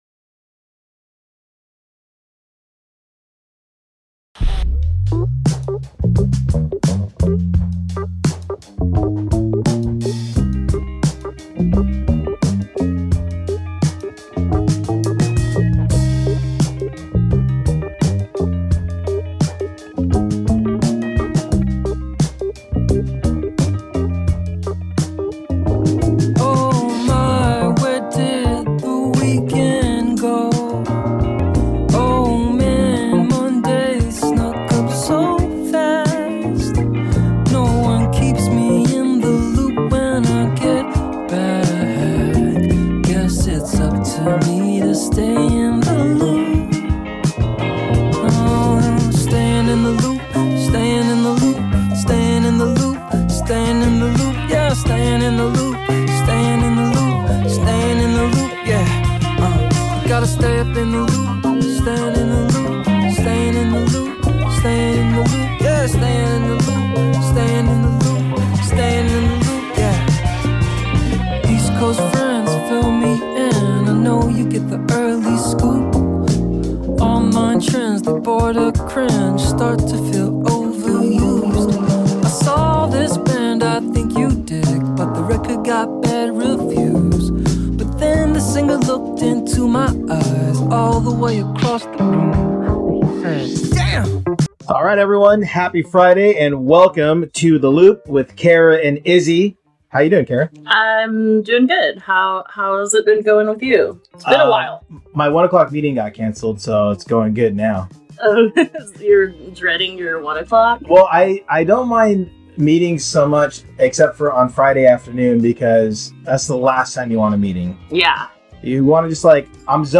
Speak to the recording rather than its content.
This episode of Lunchtime on The Loop aired live on CityHeART Radio on Friday, Aug. 25 at 12:30pm.